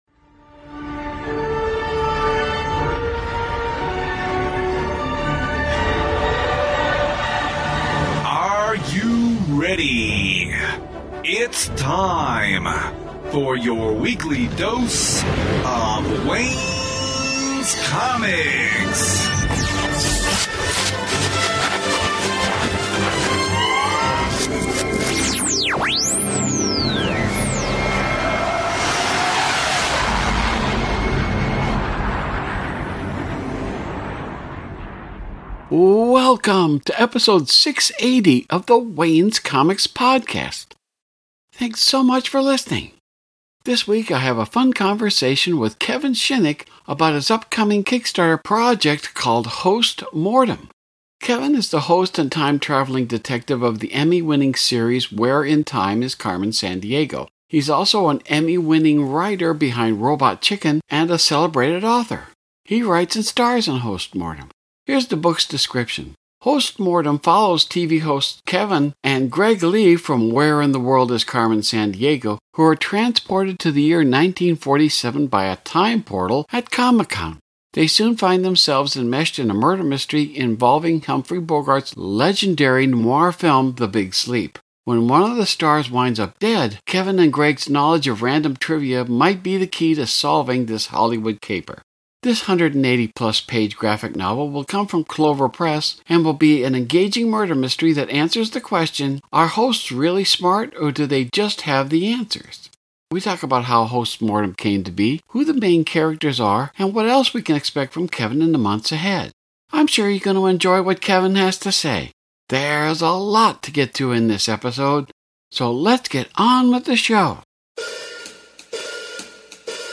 Episode #675: Interview